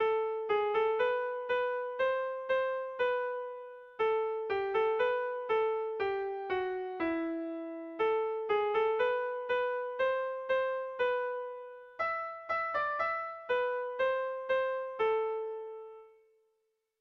Irrizkoa
Lau puntuko berdina, 8 silabaz
8A / 8A / 8B / 8B
8/8/8/8AB